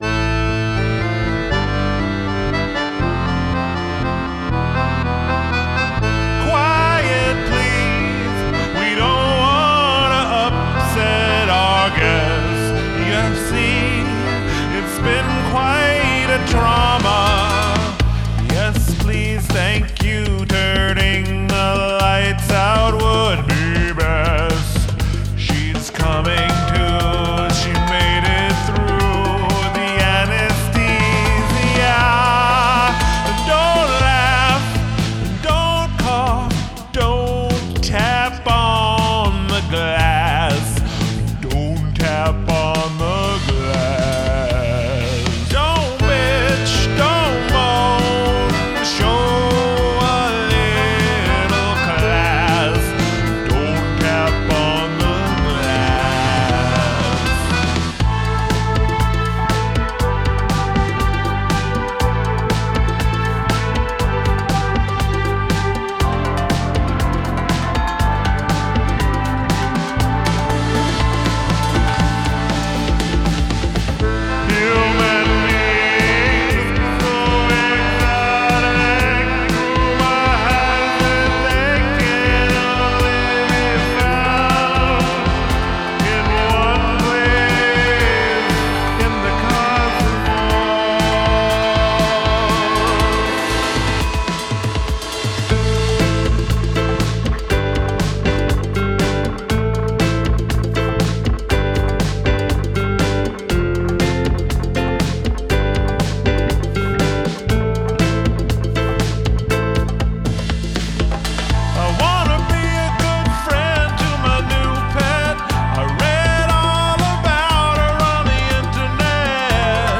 Love the organy thing too.